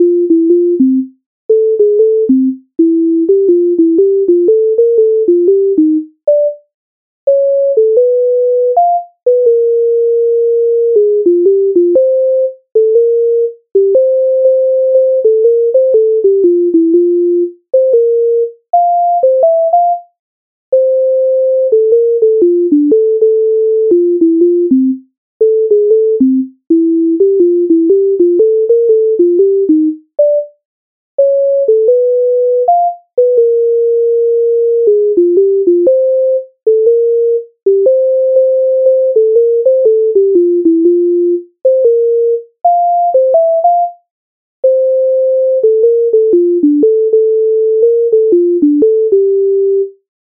MIDI файл завантажено в тональності F-dur